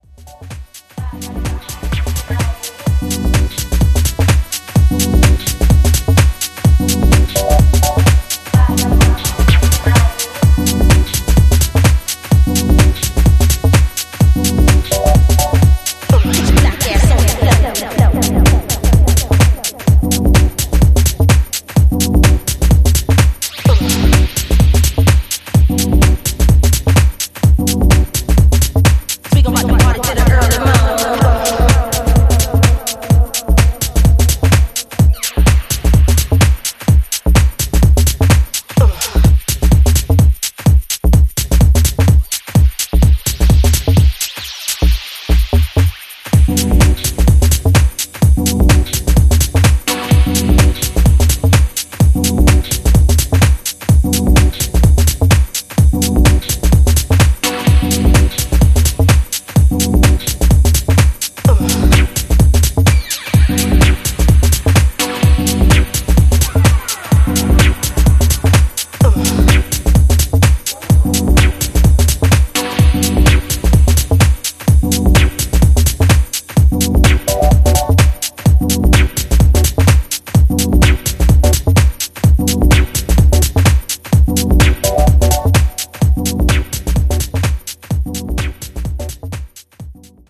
with a bouncy bassline and deep chords.